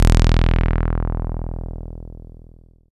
78.02 BASS.wav